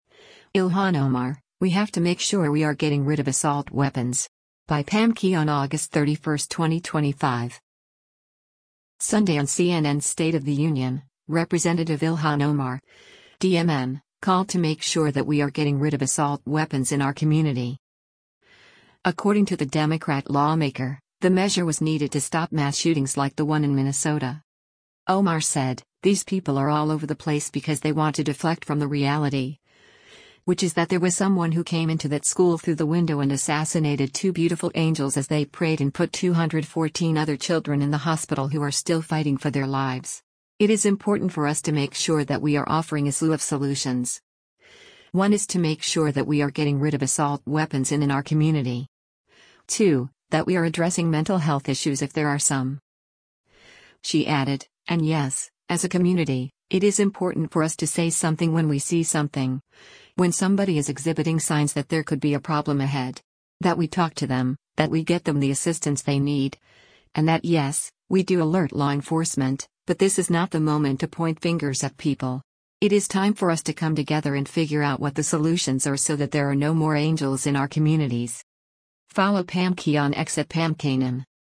Sunday on CNN’s “State of the Union,” Rep. Ilhan Omar (D-MN) called to “make sure that we are getting rid of assault weapons in our community.”